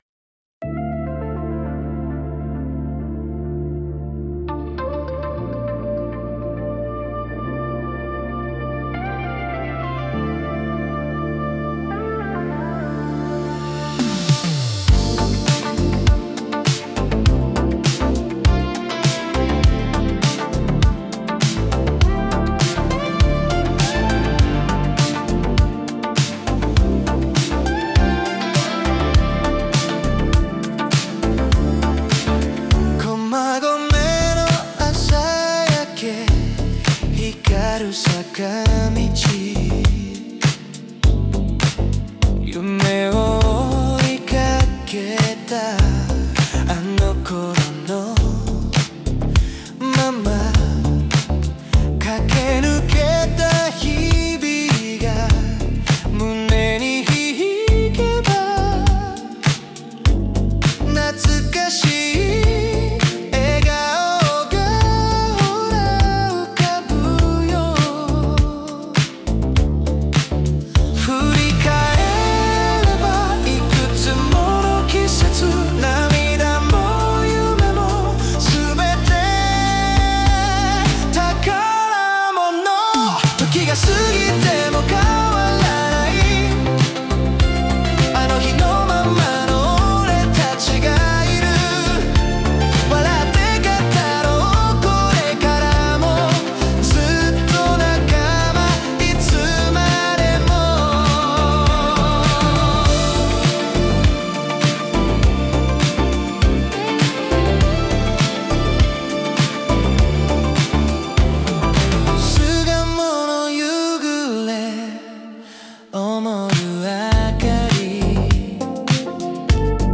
R&B-inspired City Pop groove